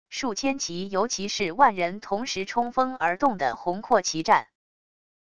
数千骑尤其是万人同时冲锋而动的宏阔骑战wav音频